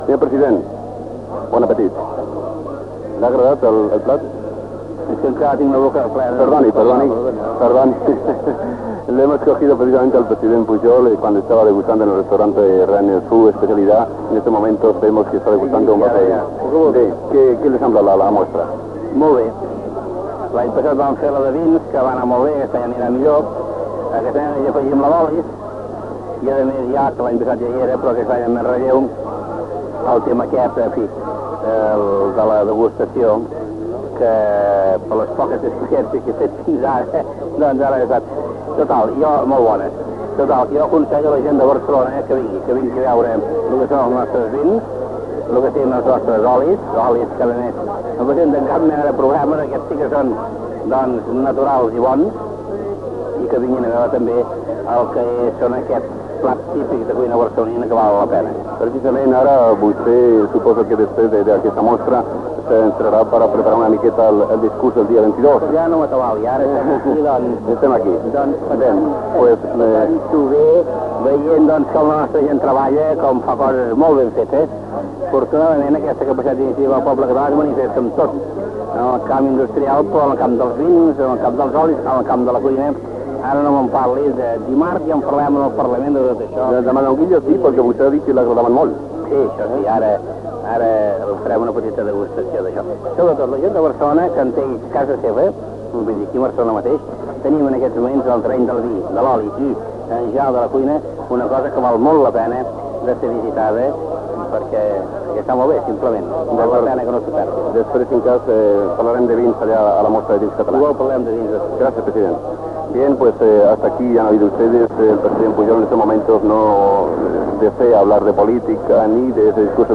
Connexió amb la II Mostra de la cuina barcelonina que es feia a la Rambla de Catalunya de Barcelona. Entrevista al president de la Generalitat Jordi Pujol.